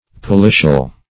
Policial \Po*li"cial\, a.